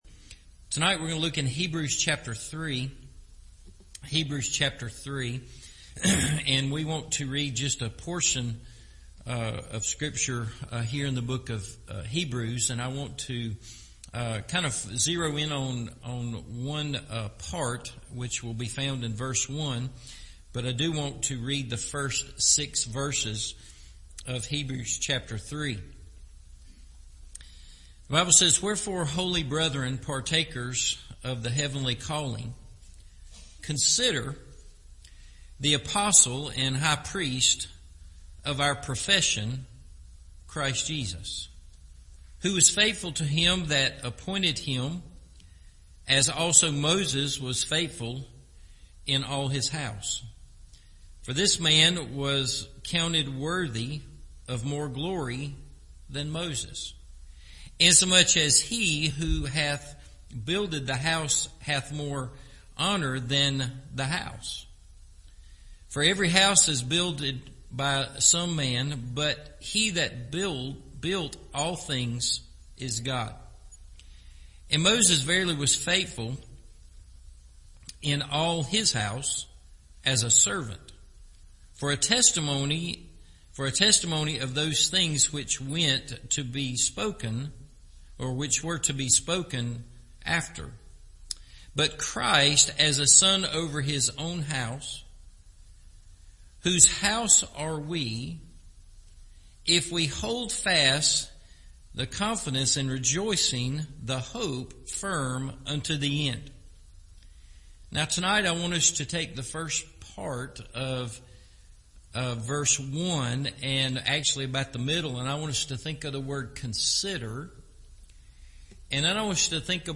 Consider Jesus – Evening Service